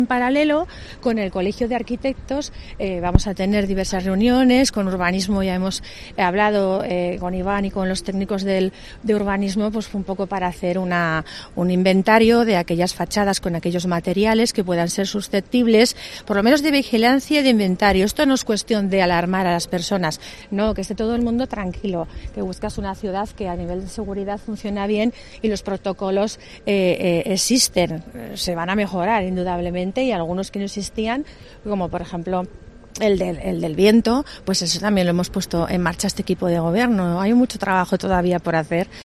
La alcaldesa de Huesca habla de la realización de un inventario de fachadas